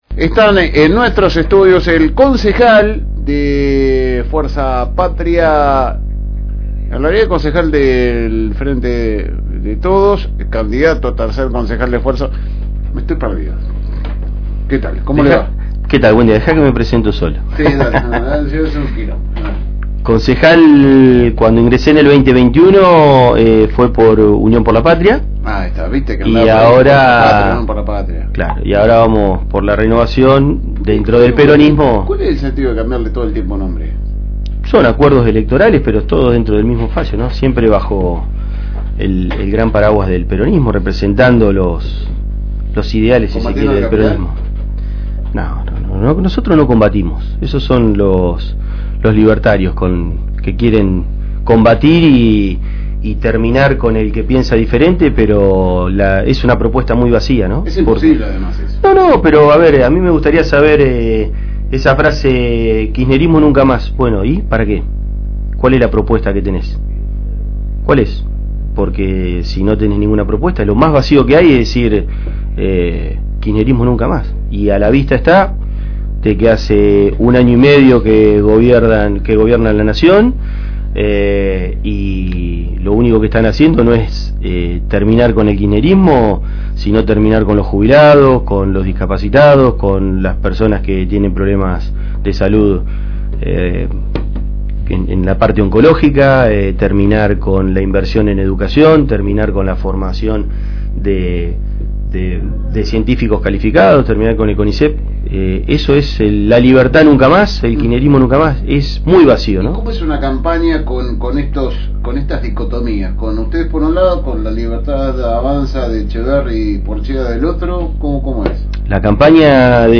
A días de las elecciones del 7 de septiembre donde todos los bonaerenses van a elegir las nuevas autoridades y en Lobos toca renovar 8 bancas y 3 consejeros. El que busca ser reelegido es el actual concejal Matias Thea que pasó por los estudios de la FM Reencuentro 102.9.